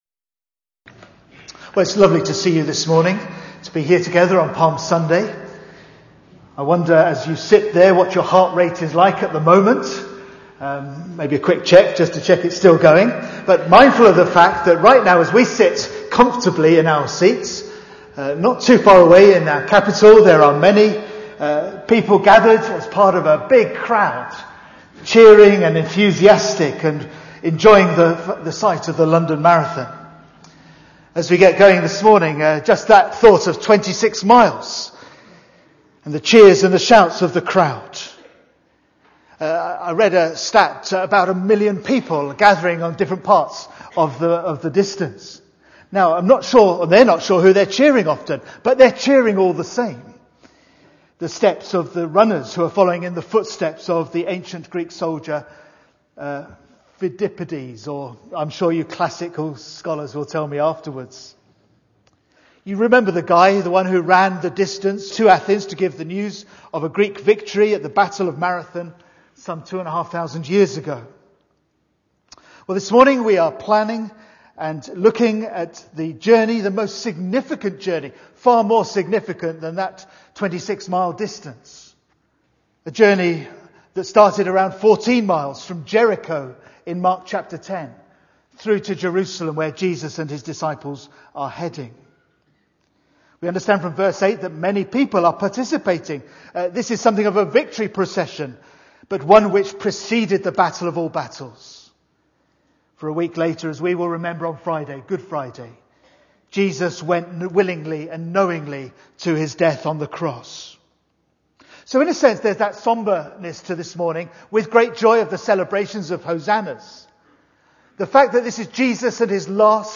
The Welcomed King - Cambray Baptist Church